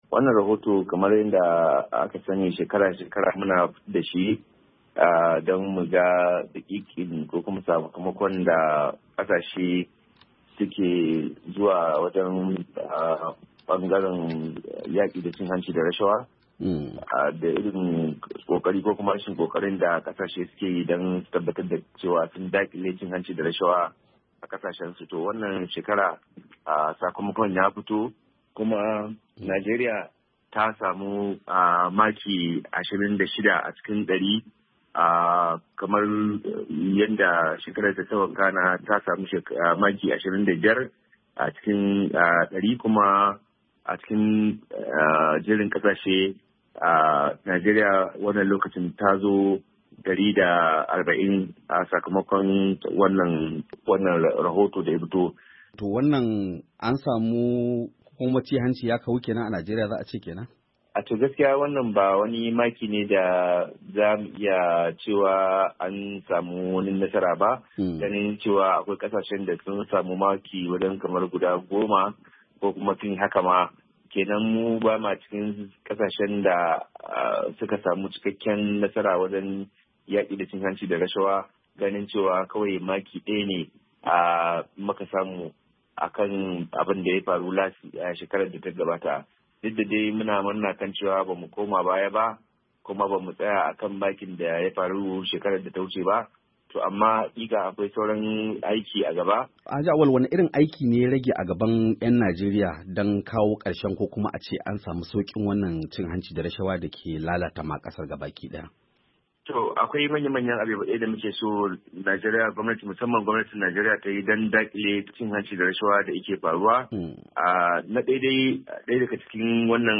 A saurari hiran Wakilin Muryar Amurka